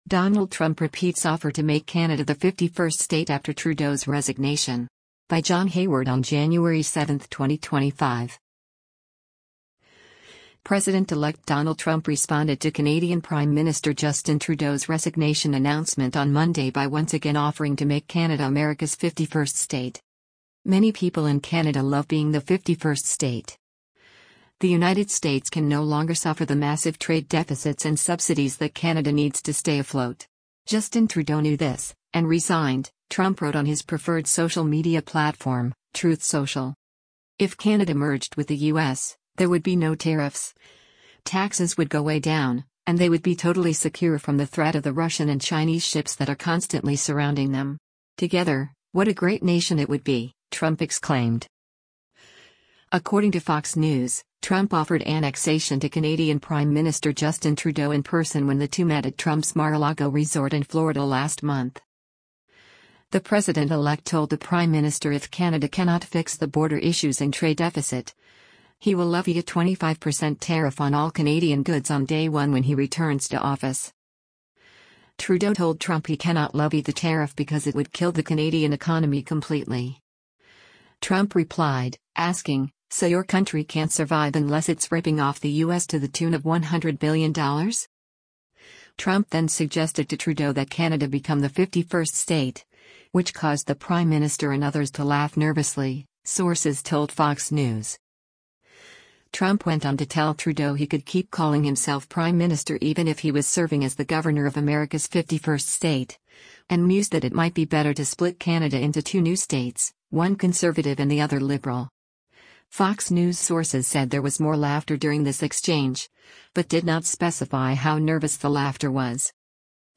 President-elect Donald Trump speaks during a news conference at Mar-a-Lago, Tuesday, Jan.